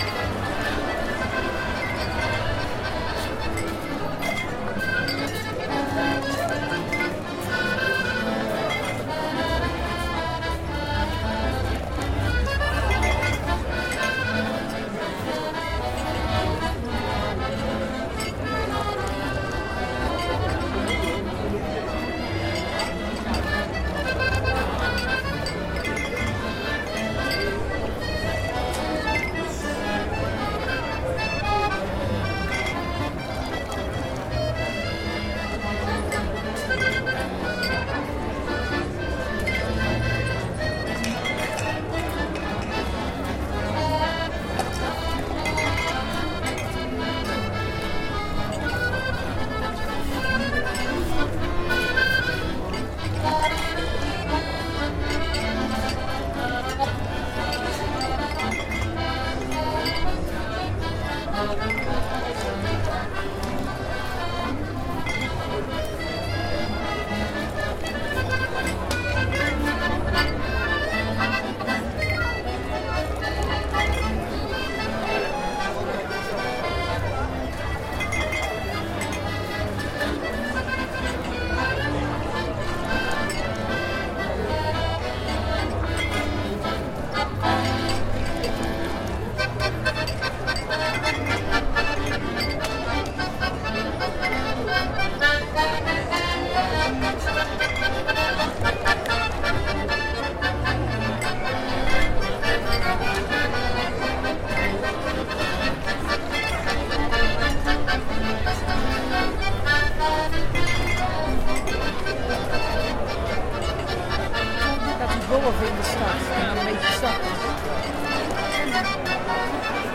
pirate-saloon-loop.ogg